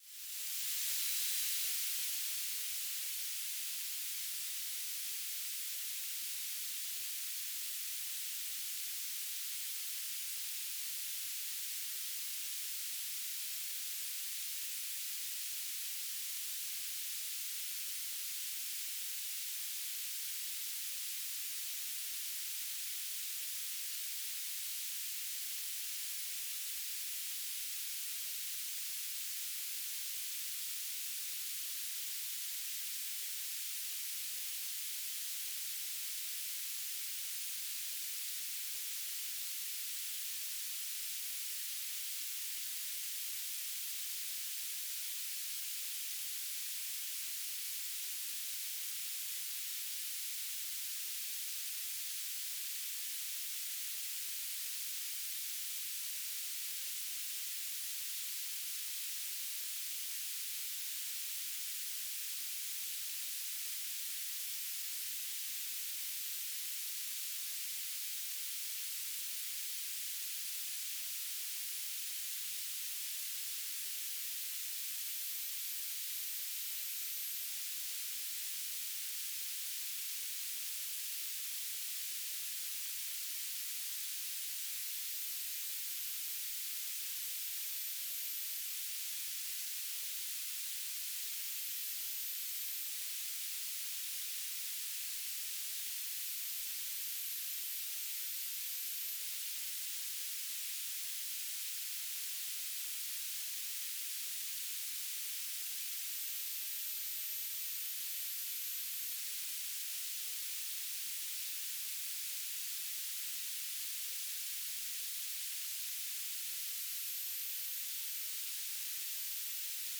"transmitter_description": "BPSK1k2 TLM",
"transmitter_mode": "BPSK",